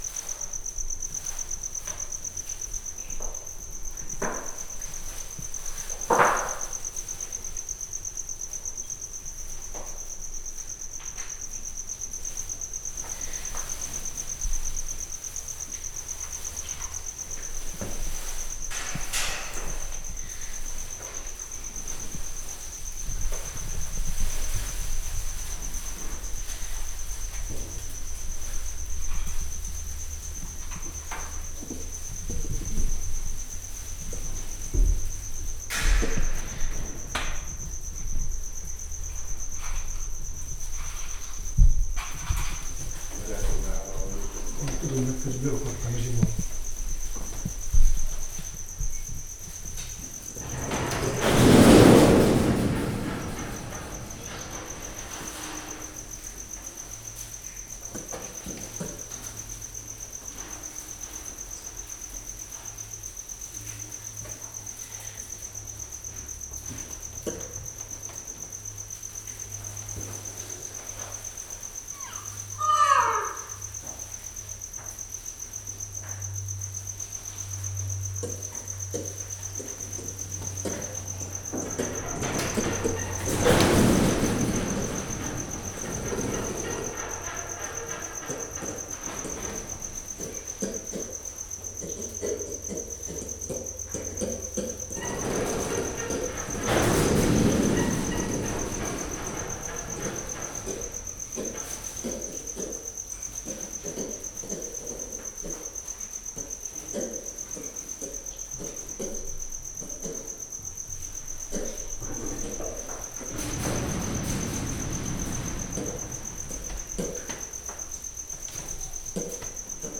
Directory Listing of /_MP3/allathangok/jaszberenyizoo2016_professzionalis/gyurusfarkumaki/